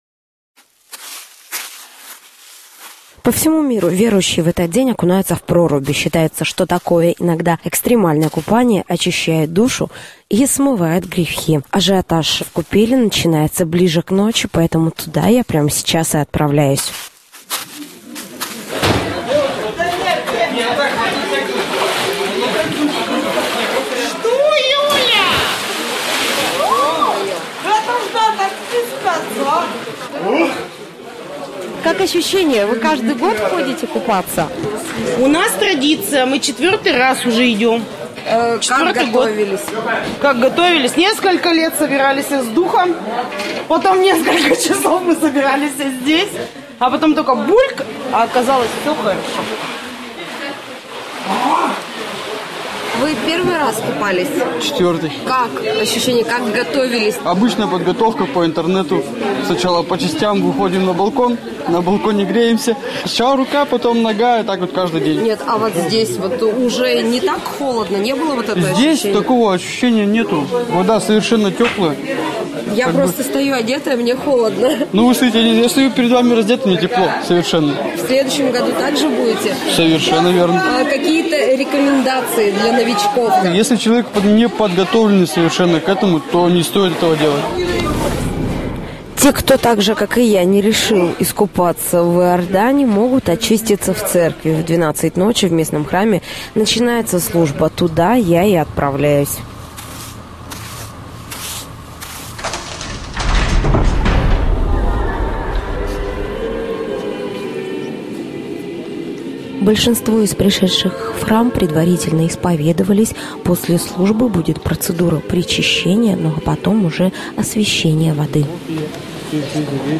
Reportazh_Kreshhenie_2019.mp3